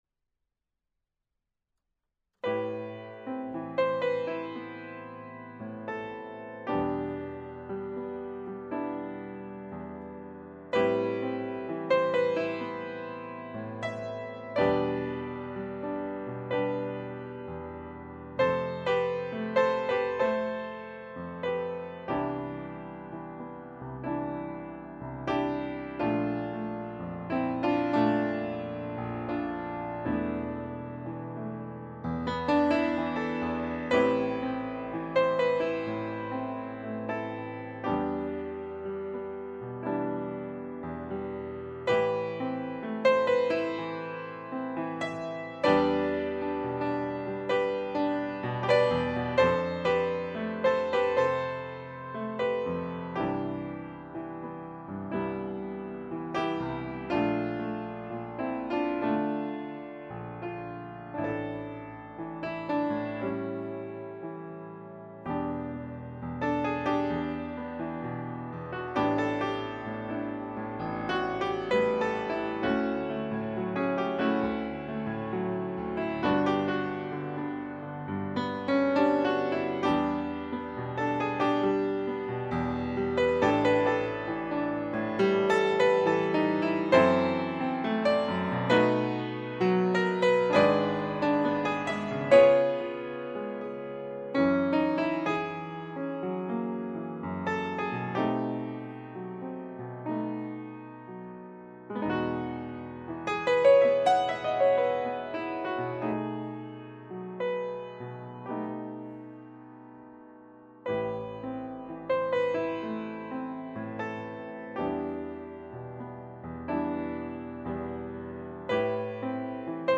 piano - romantique - melancolique - nostalgique - melodique